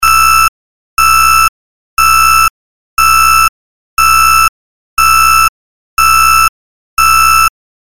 جلوه های صوتی
دانلود آهنگ هشدار 9 از افکت صوتی اشیاء
دانلود صدای هشدار 9 از ساعد نیوز با لینک مستقیم و کیفیت بالا